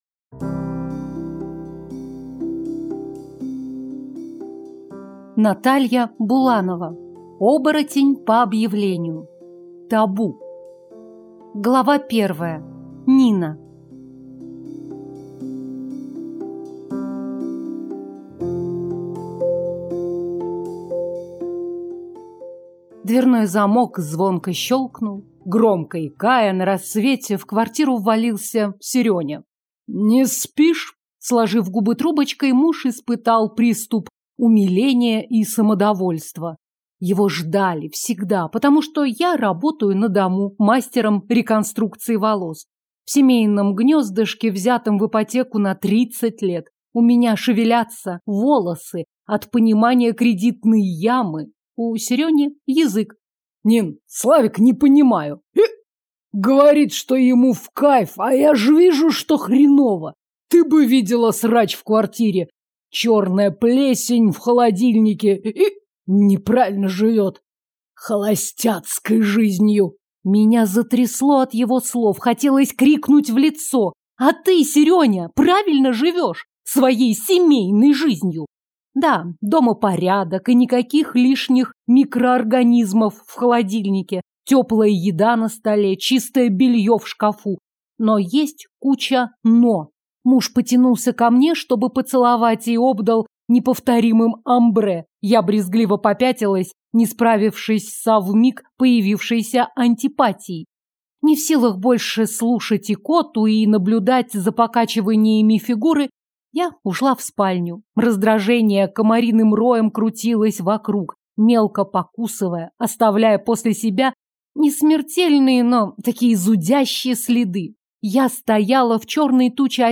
День прошел (слушать аудиокнигу бесплатно) - автор Надежда Тэффи